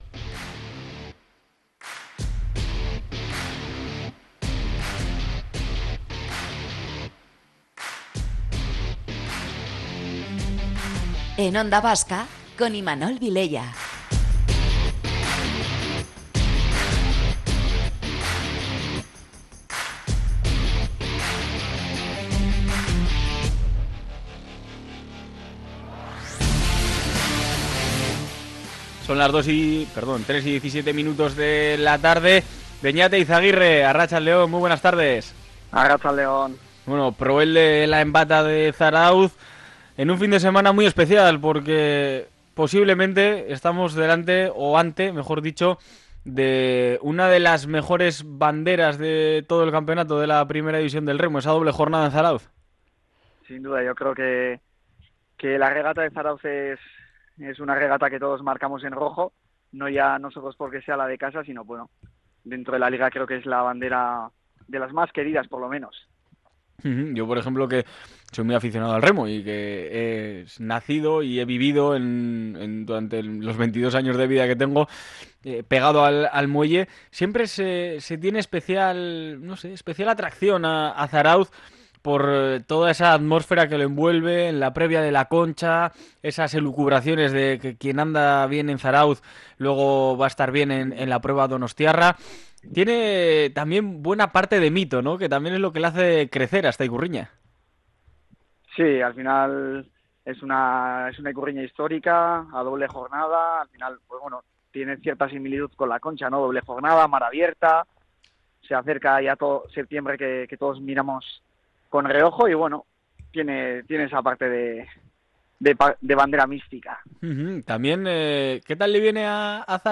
Entrevistas deportivas